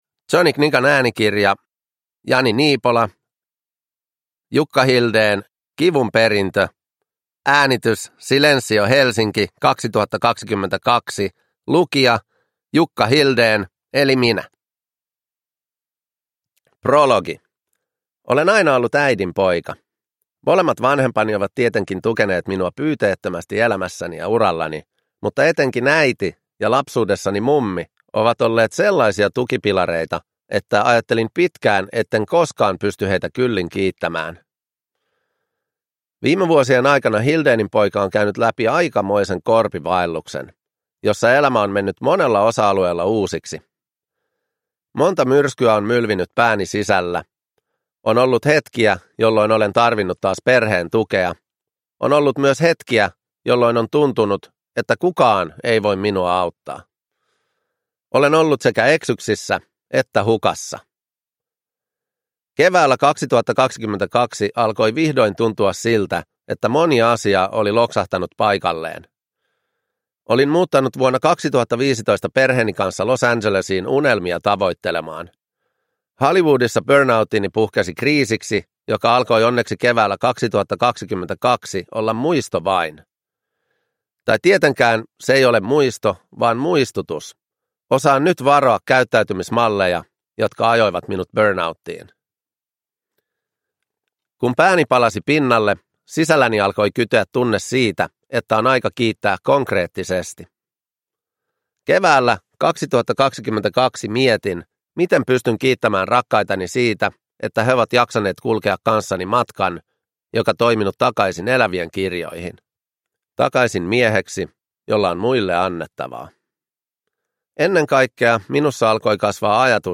Jukka Hildén – Kivun perintö – Ljudbok
Uppläsare: Jukka Hildén